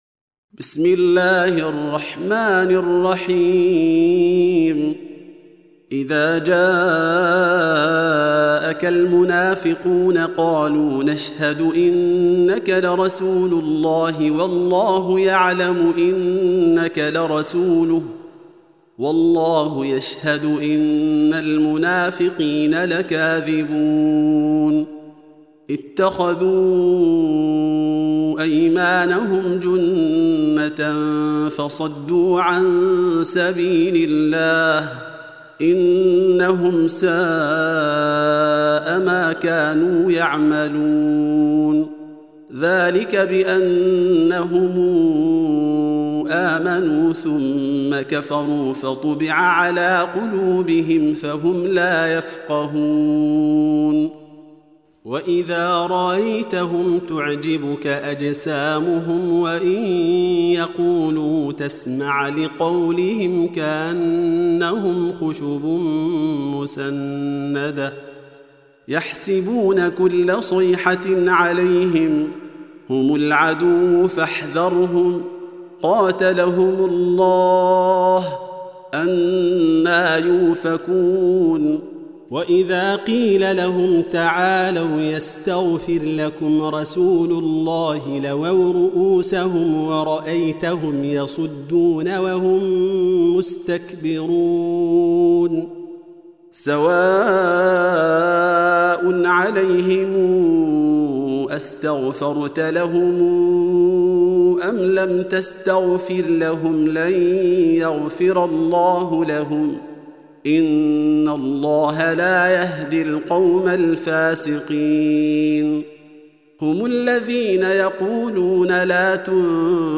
Reciting Murattalah Audio for 63. Surah Al-Munafiqûn سورة المنافقون N.B *Surah Includes Al-Basmalah